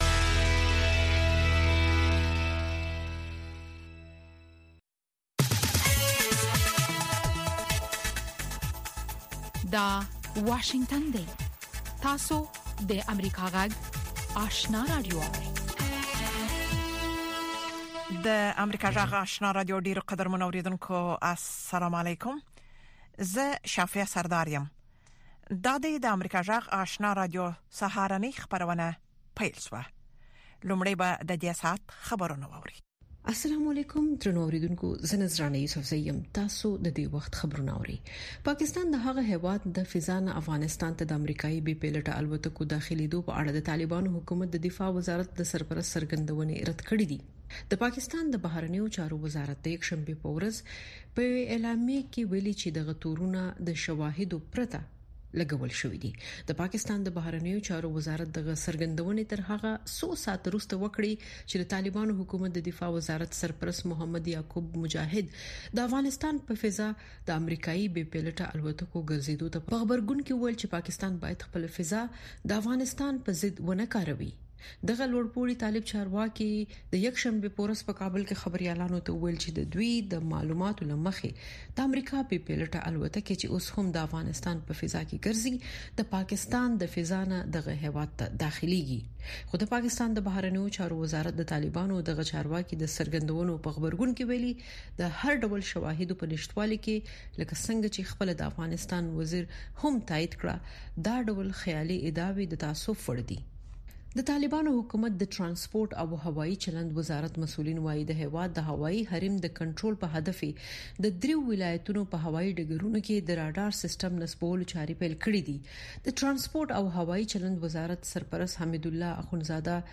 سهارنۍ خبري خپرونه